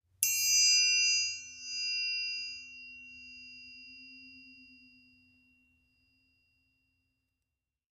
Triangle Sml Strike Spins 4